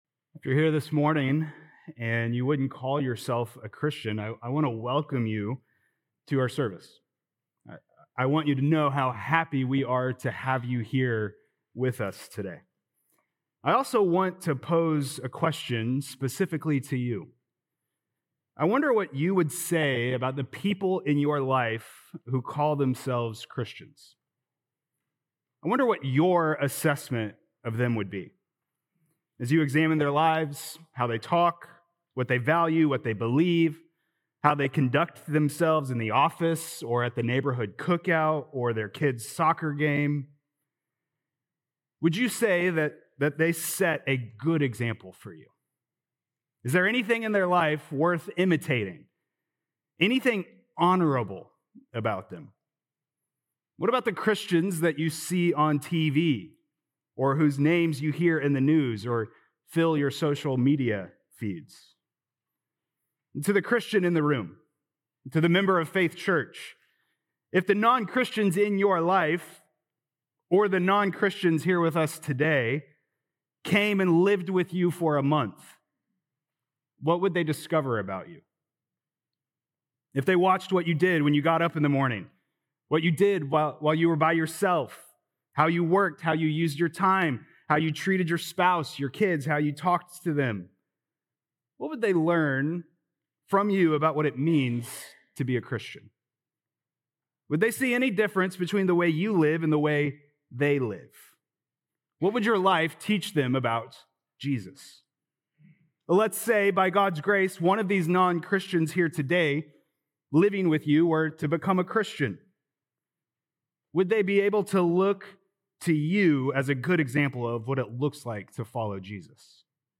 August 24th Sermon | Philippians 2:19-30